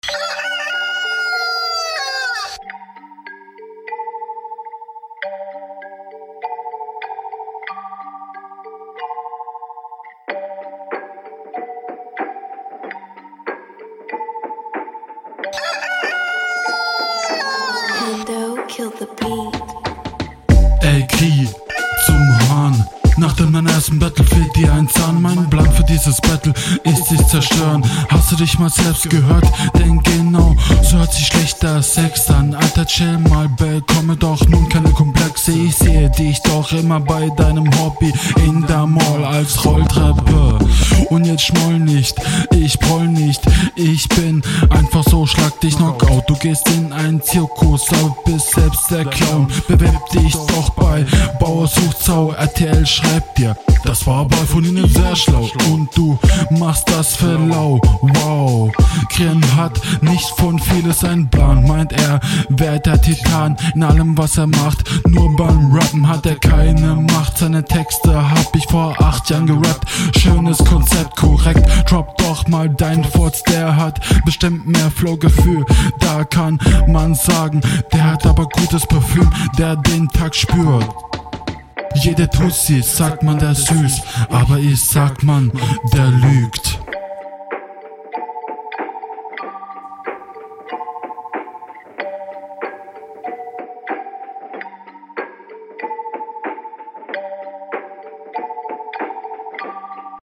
Kauf dir ein Popfilter.